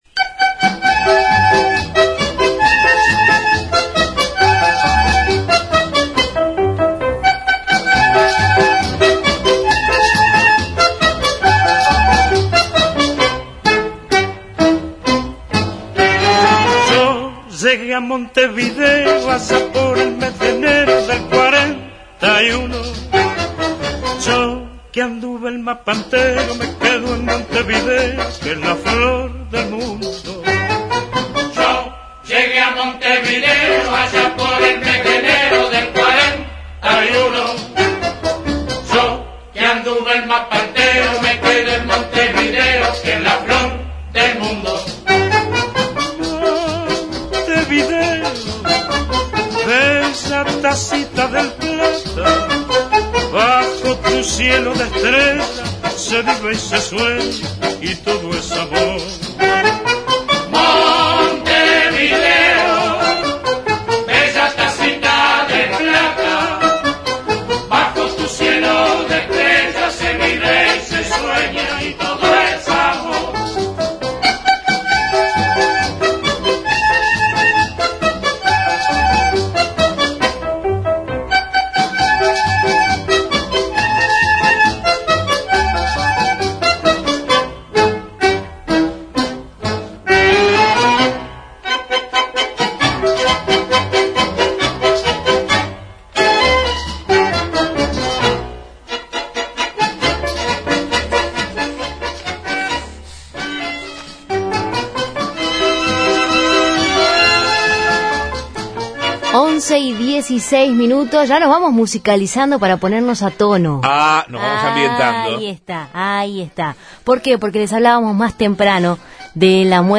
En dialogo con Justos y pecadores